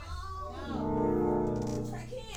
The organ can be zipped